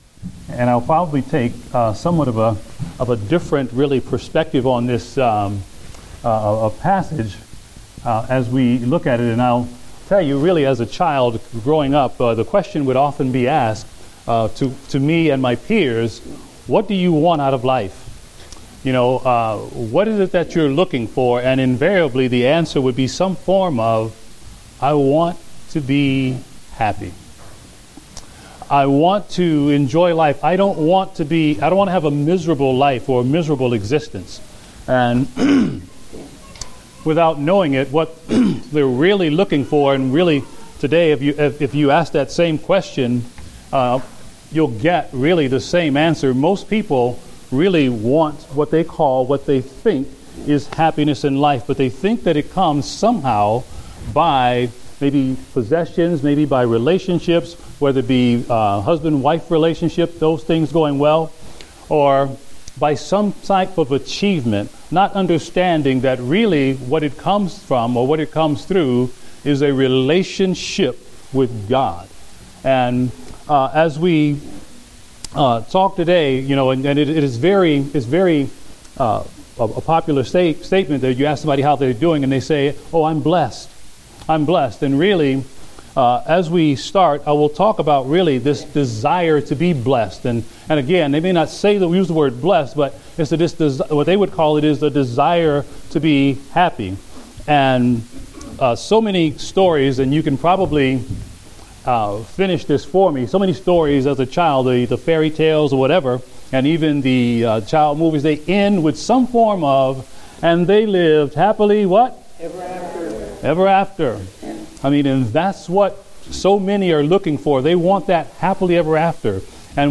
Date: April 26, 2015 (Adult Sunday School)